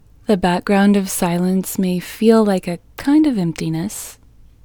LOCATE IN English Female 12